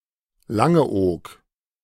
Langeoog (German pronunciation: [ˈlaŋəˌʔoːk]